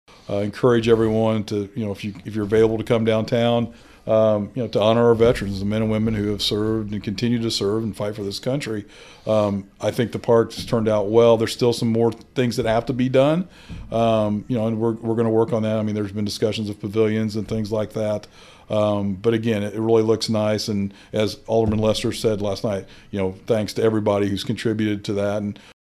Speaking on this week’s podcast, “Talking about Vandalia,” Mayor Knebel says he hopes everyone can come out to honor our Veterans and also to see the continued improvement at the downtown Veteran’s Memorial.